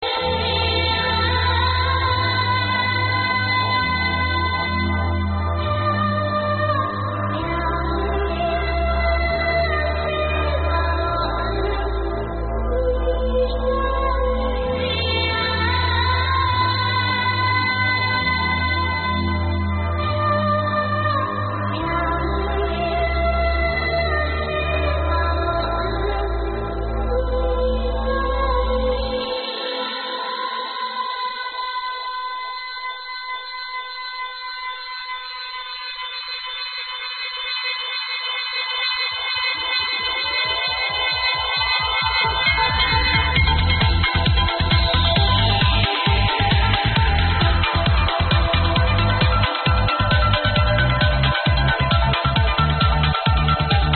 Massive Break,